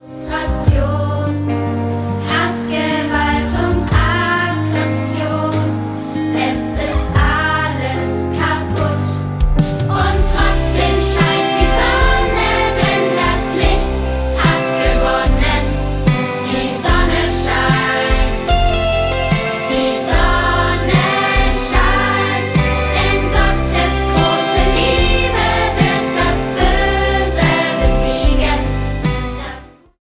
Musical-CD